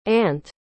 Nos Estados Unidos, ant é pronunciada como /ænt/ (com o mesmo som do “a” em “cat”).